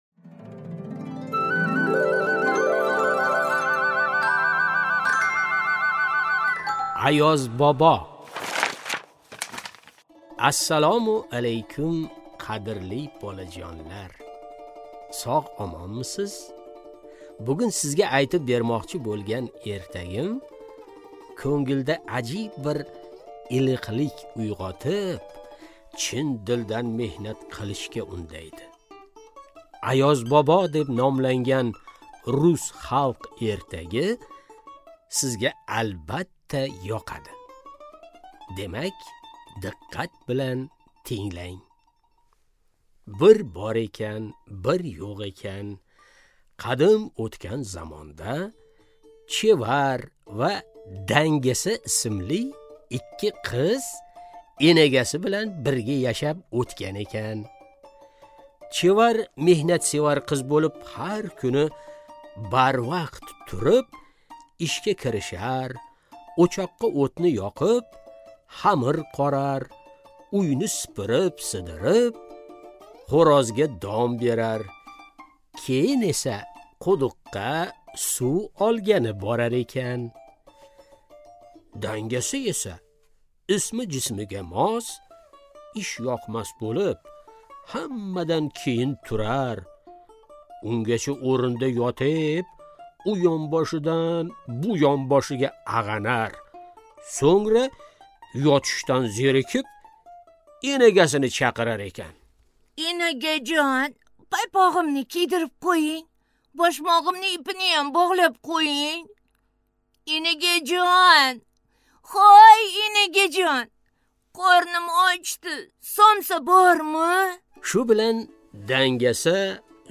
Аудиокнига Ayozbobo | Библиотека аудиокниг
Прослушать и бесплатно скачать фрагмент аудиокниги